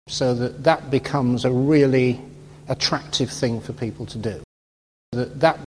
Conjunction that is usually weak, containing the colourless vowel schwa, /ə/, which is not shown in spelling.
To illustrate the distinction between the two kinds of that, here are real utterances in which native speakers say the conjunction and the demonstrative in sequence, /ðət ðat/: